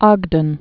(ôgdən, ŏg-)